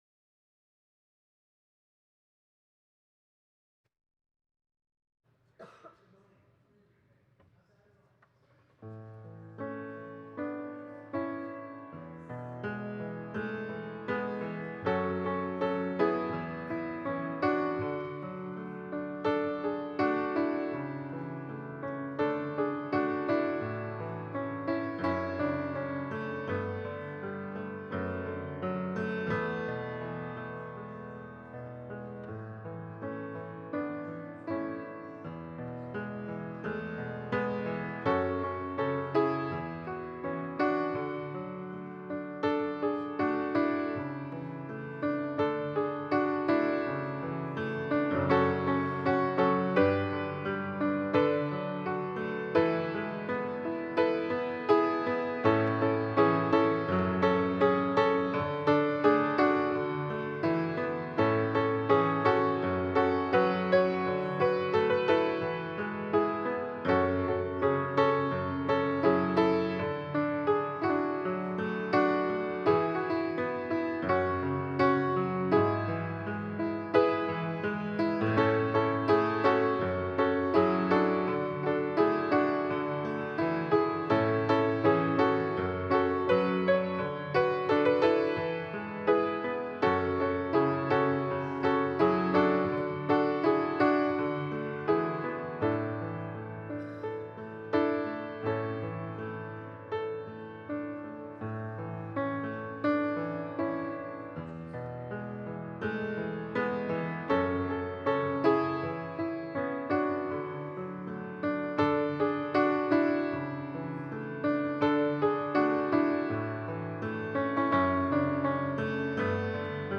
Passage: Luke 23: 33-43 Service Type: Sunday Service Scriptures and sermon from St. John’s Presbyterian Church on Sunday